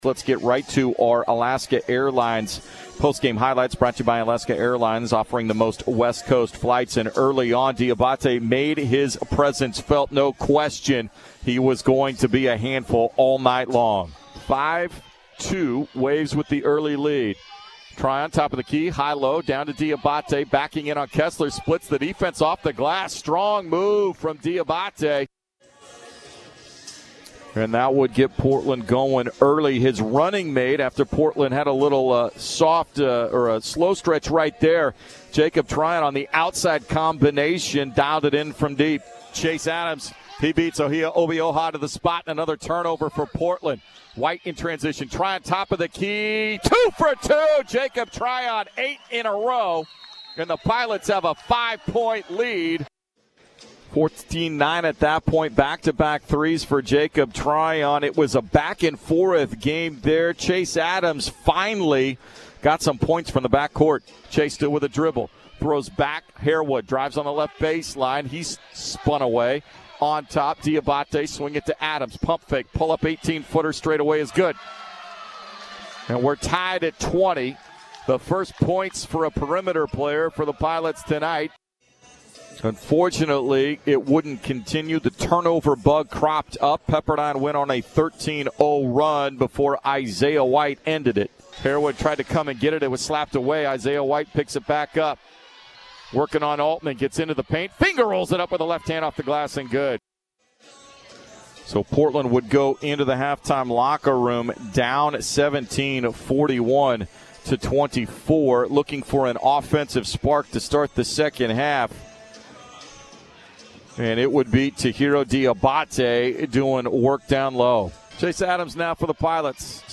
January 25, 2020 Portland dropped an 80-69 decision at Pepperdine on Jan. 25, 2020. Hear the highlights and post-game recap from 910 ESPN-Portland (KMTT).